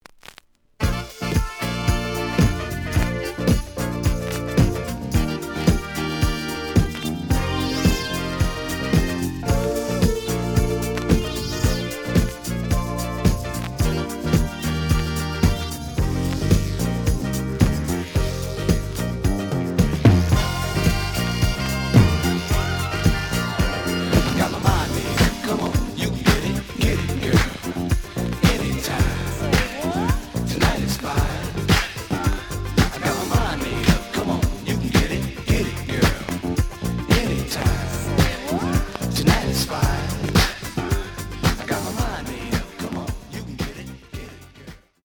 The audio sample is recorded from the actual item.
●Genre: Disco
Some noise on beginning of both sides due to scratches.)